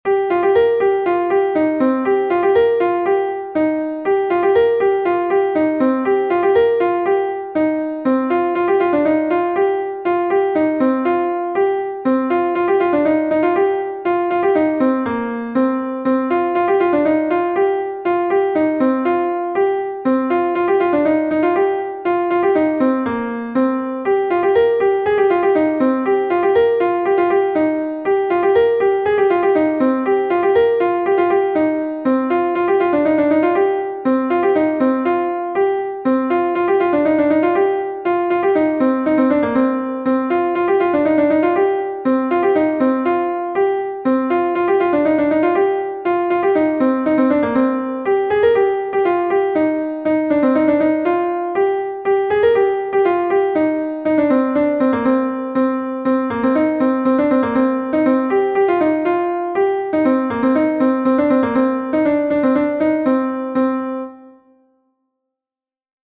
Gavotenn Er Gemene/Lokmaleù is a Gavotte from Brittany